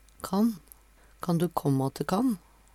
kan - Numedalsmål (en-US)